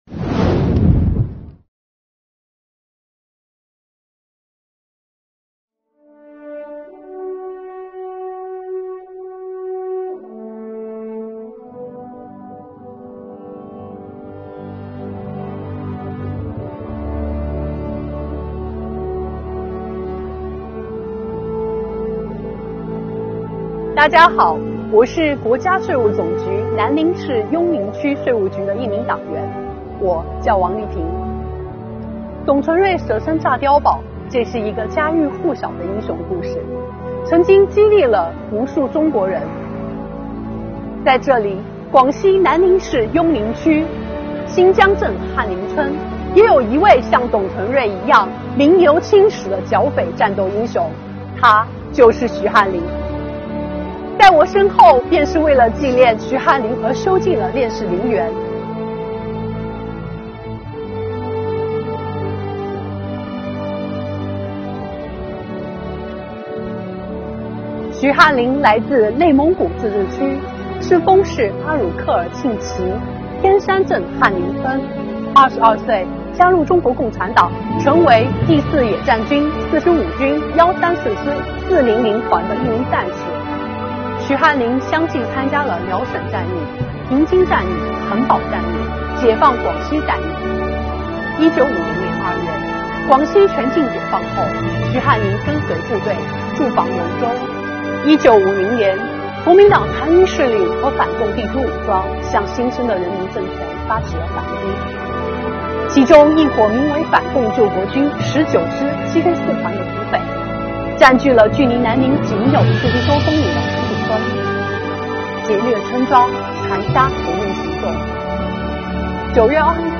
今天发布南宁市邕宁区税务局青年说党史之《缅怀革命先烈，传承红色基因——永远铭记董存瑞式的战斗英雄徐汉林》